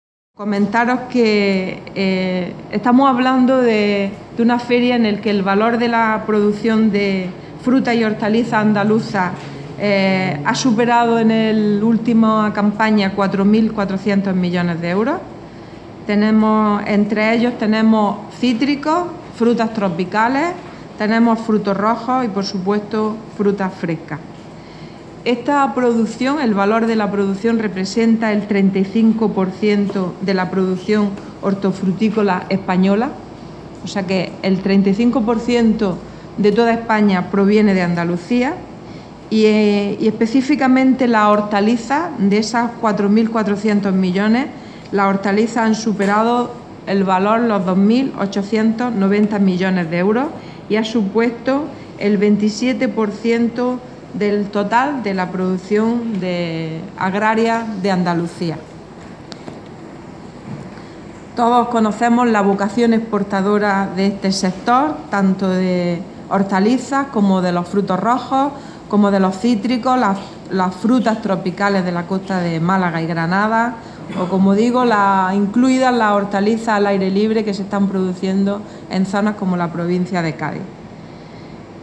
Declaraciones Carmen Ortiz sobre valor producción hortofrutícola